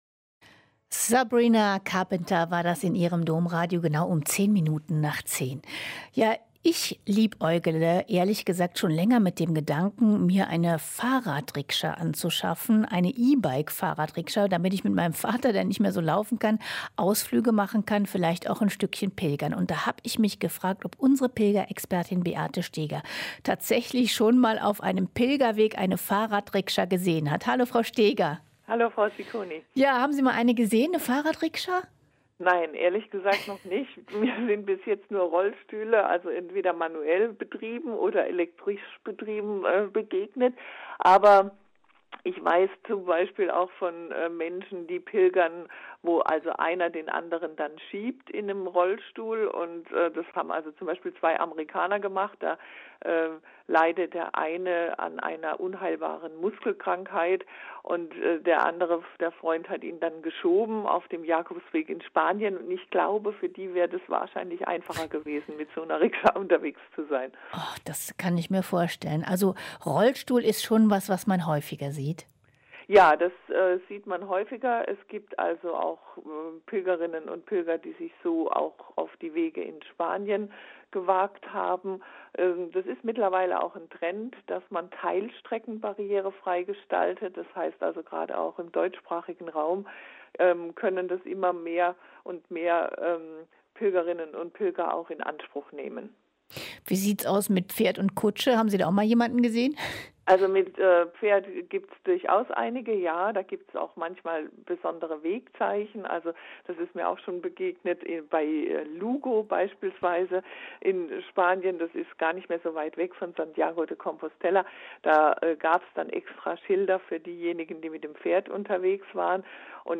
Im Interview verrät sie, wie man sich auf eine Pilgerreise mit Hilfsmitteln vorbereiten kann.